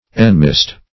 Enmist \En*mist"\